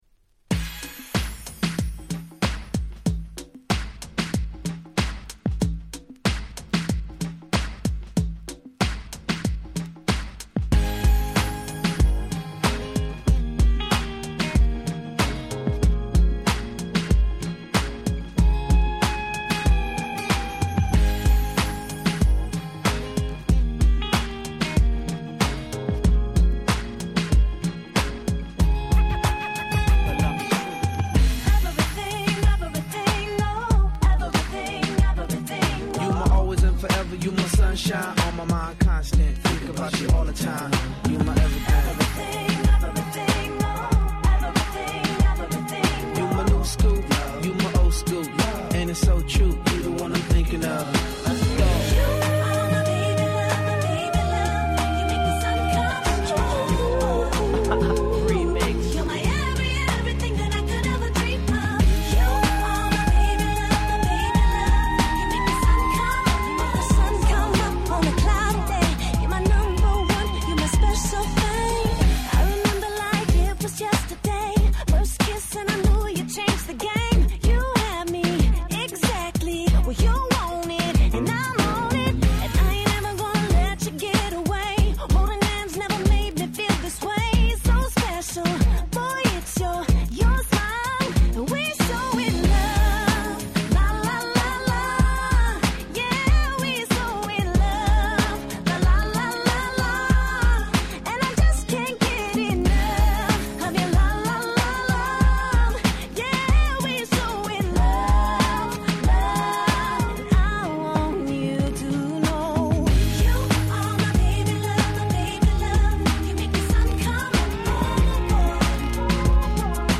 07' Smash Hit R&B♪